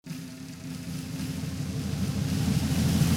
Minus Lead Guitar Rock 0:58 Buy £1.50